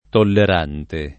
vai all'elenco alfabetico delle voci ingrandisci il carattere 100% rimpicciolisci il carattere stampa invia tramite posta elettronica codividi su Facebook tollerante [ toller # nte ] (ant. tolerante [ toler # nte ]) part. pres. di tollerare e agg.